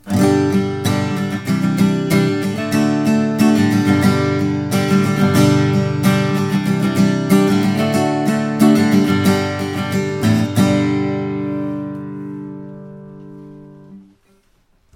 He made under fixed conditions samples of each guitar.
Rythm 3